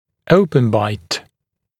[‘əupən baɪt][‘оупэн байт]открытый прикус, отсутствие контакта резцов, дизокклюзия